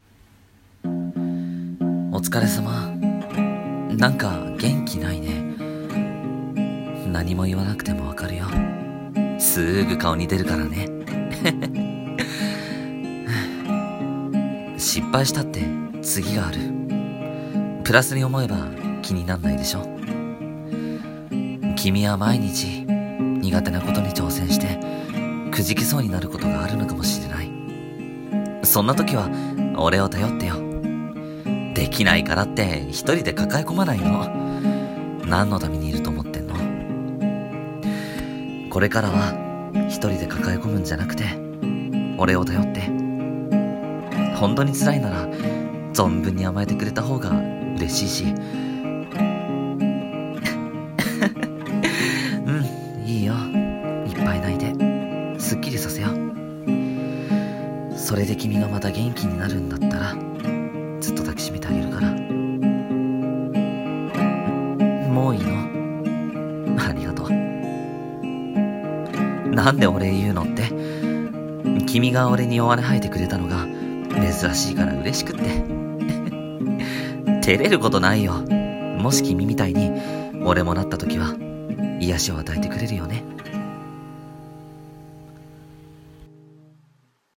【一人声劇】落ち込んだ君を励まします☆